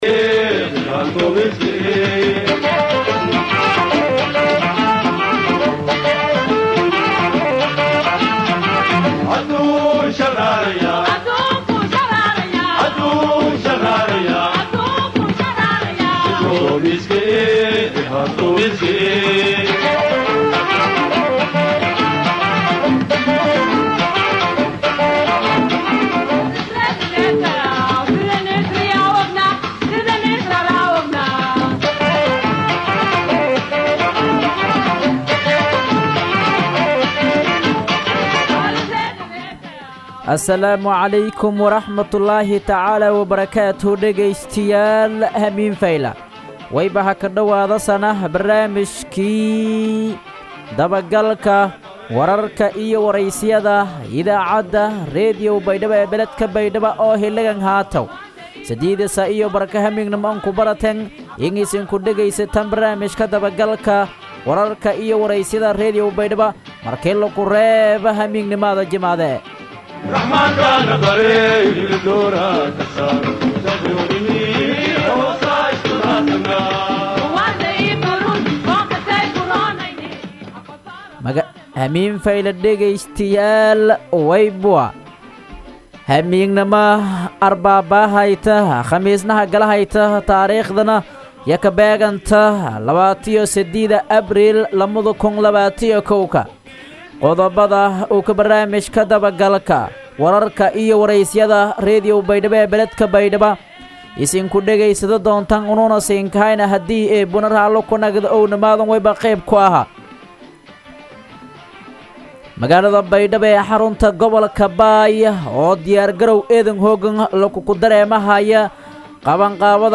BAYDHABO–BMC:–Dhageystayaasha Radio Baidoa ee ku xiran Website-ka Idaacada Waxaan halkaan ugu soo gudbineynaa Barnaamijka Dabagalka Wararka iyo Wareysiyada ee ka baxay Radio Baidoa.